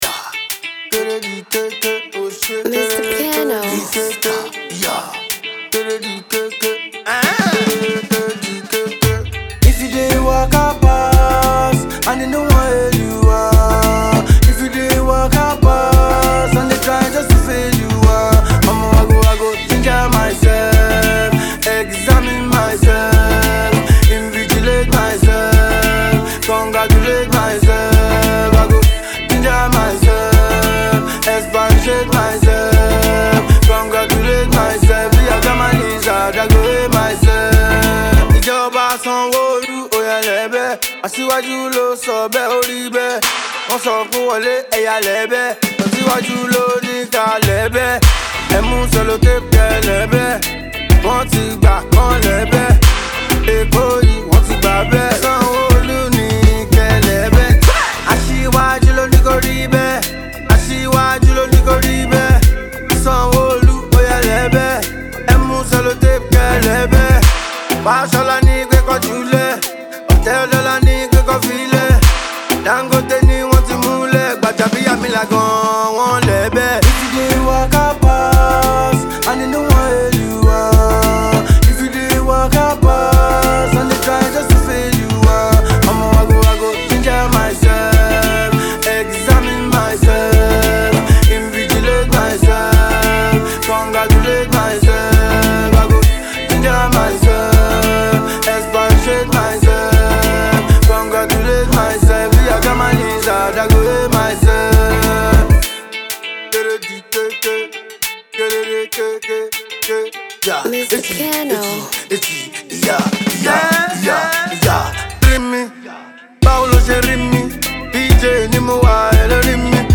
smooth track